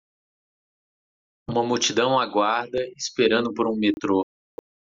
Pronounced as (IPA) /muw.t͡ʃiˈdɐ̃w̃/